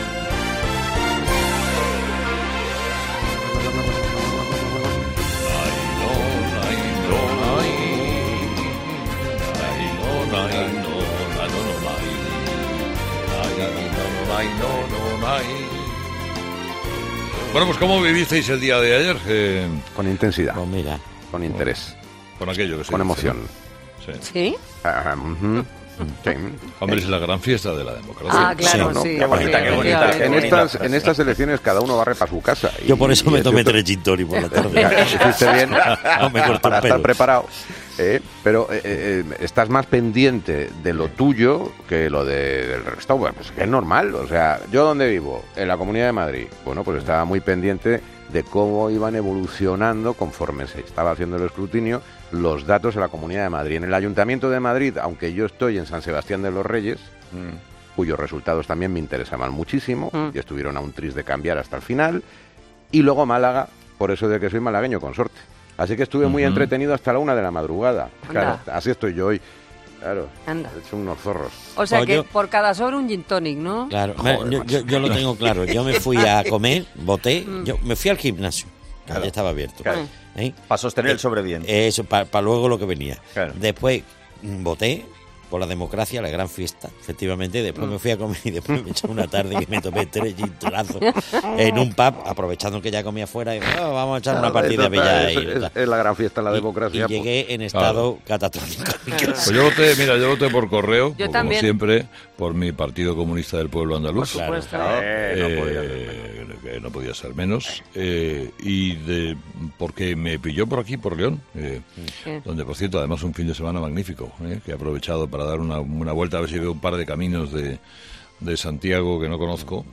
AUDIO: Este lunes los oyentes de Herrera han relatado sus impresiones sobre el superdomingo electoral
Los fósforos es el espacio en el que Carlos Herrera habla de tú a tú con los ciudadanos, en busca de experiencias de vida y anécdotas deliciosas, que confirman el buen humor y cercanía de los españoles.